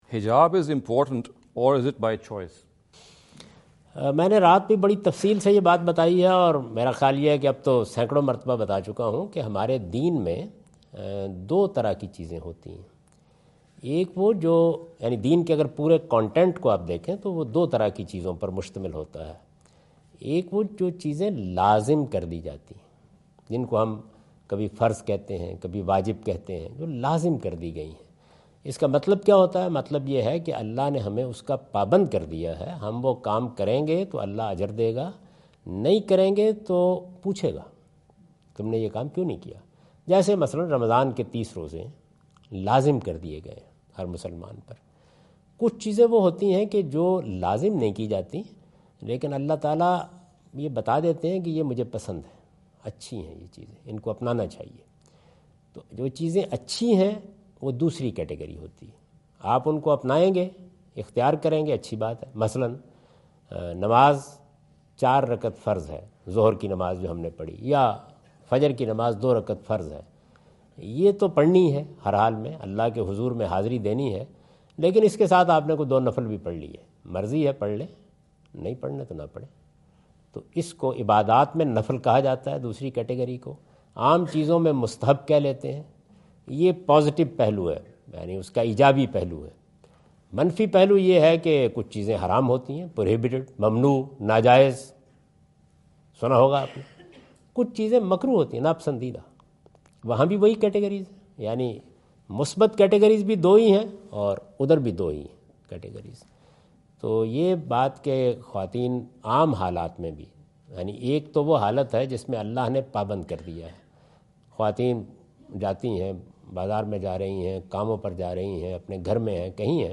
In this video Javed Ahmad Ghamidi answer the question about "is hijab obligatory or optional?" asked at East-West University Chicago on September 24,2017.